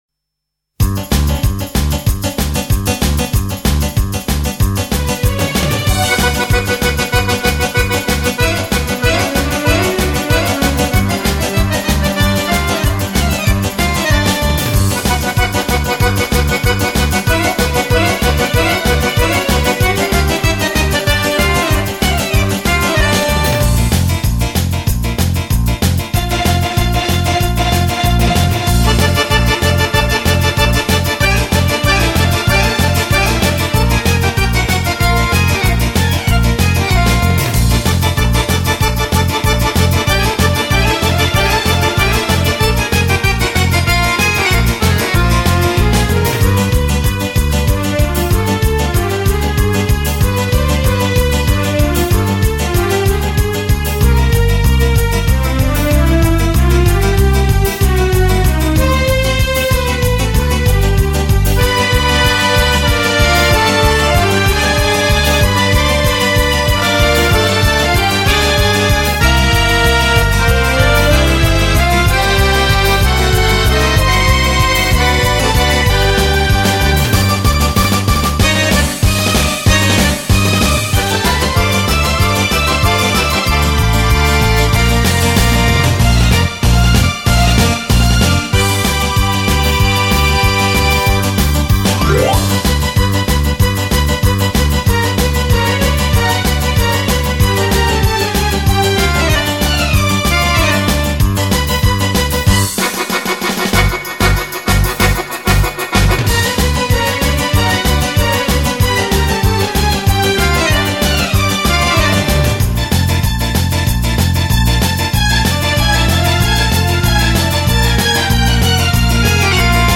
手風琴獨奏
錄音制式：ADD
专辑格式：DTS-CD-5.1声道
專輯收錄國內外手風琴暢銷金曲，旋律優美，曲曲動聽。